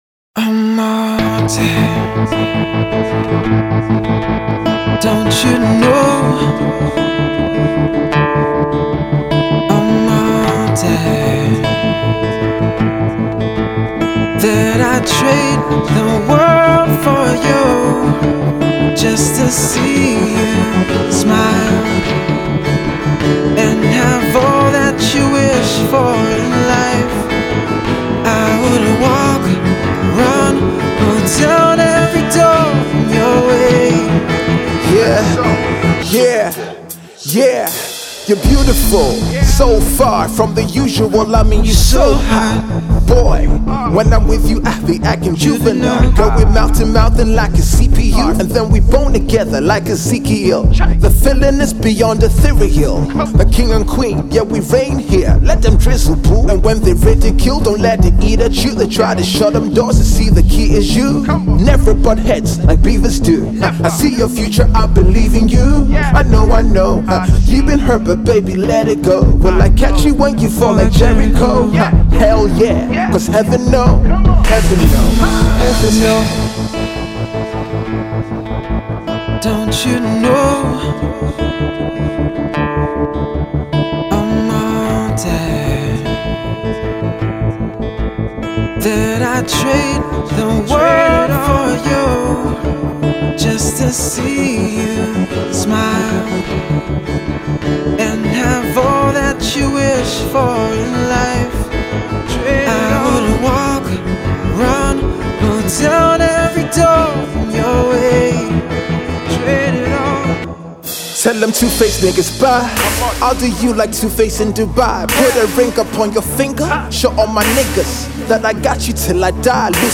classic rock ballad
You can also peep the remix right here!